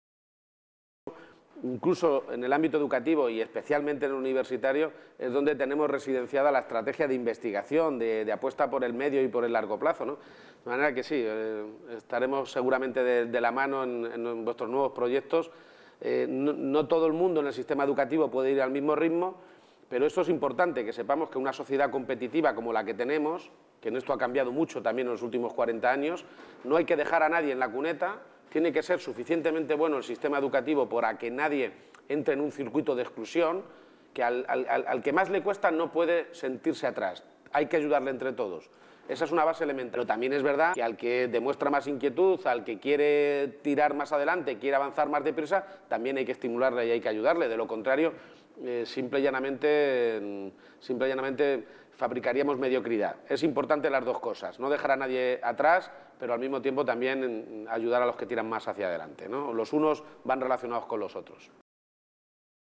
Presidente García-Page: videoconferencia II